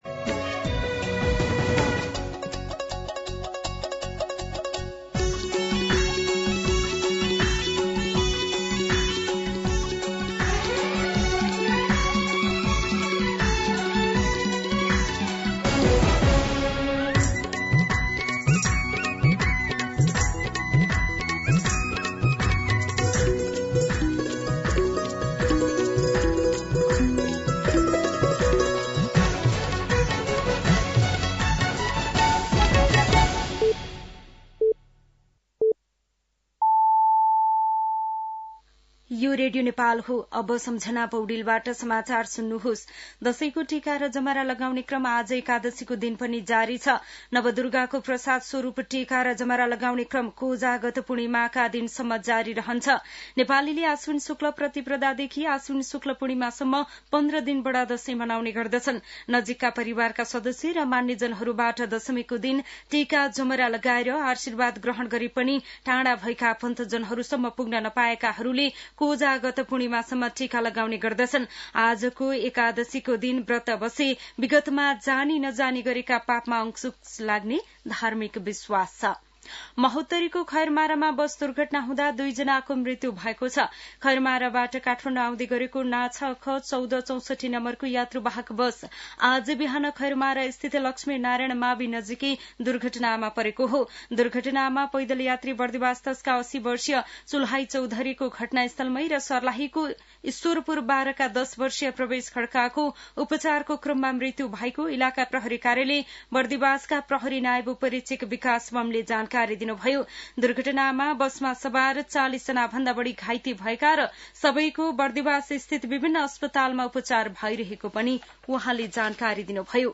मध्यान्ह १२ बजेको नेपाली समाचार : १७ असोज , २०८२
12-pm-Nepali-News.mp3